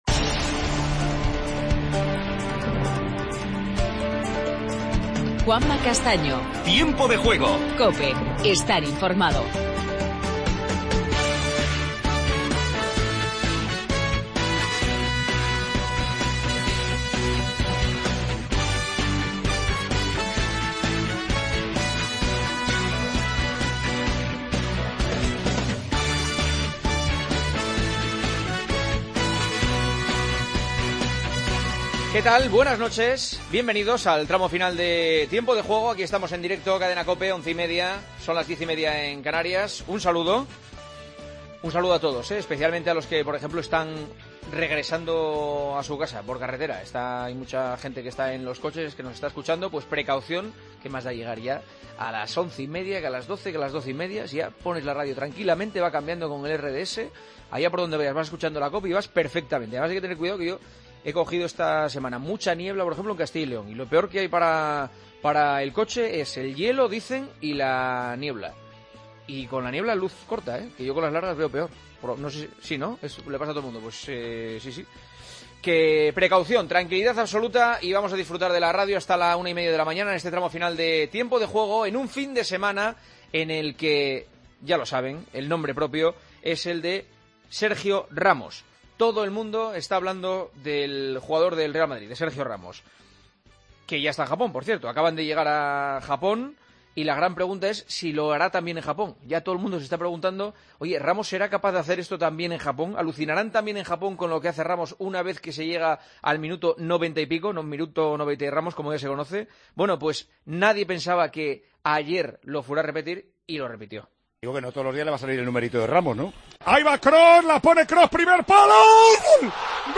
Entrevista a Joaquín, jugador del Betis. Última hora del Mundial de Clubes. Hablamos con Iborra, del Sevilla, tras marcar tres goles al Celta.